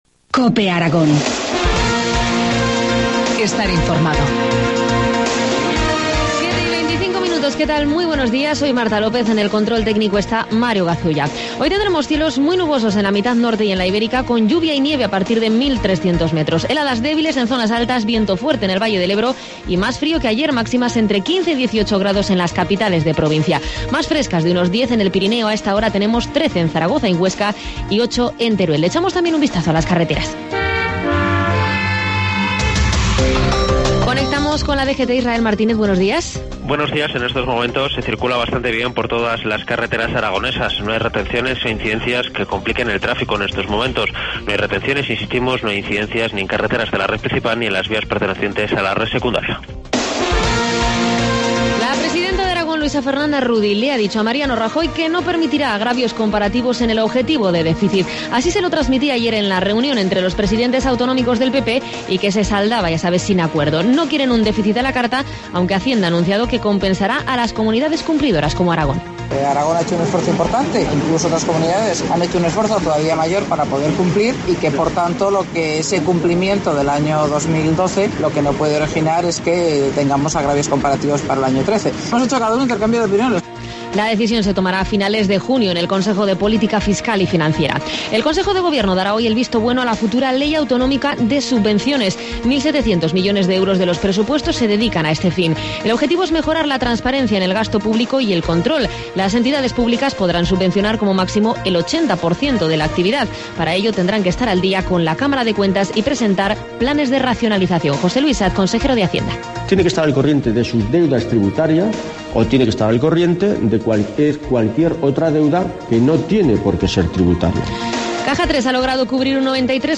Informativo matinal, martes 28 de mayo, 7.25 horas